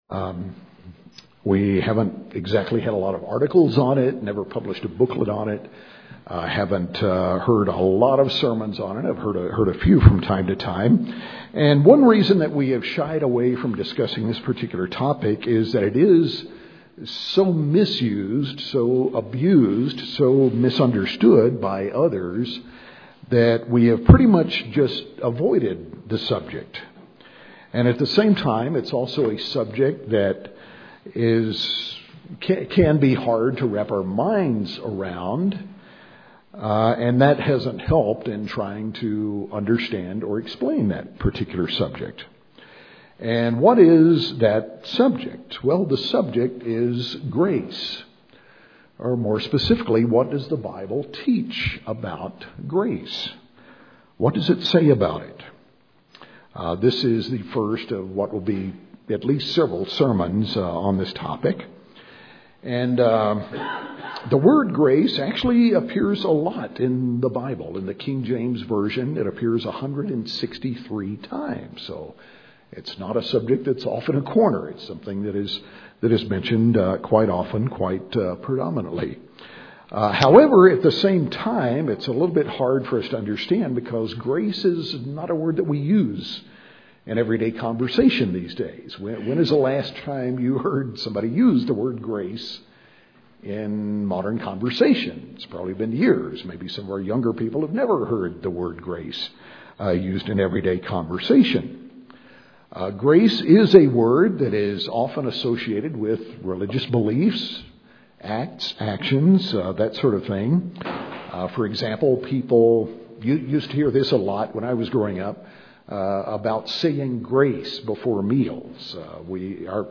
This is the first of what will be at least several sermons on this topic.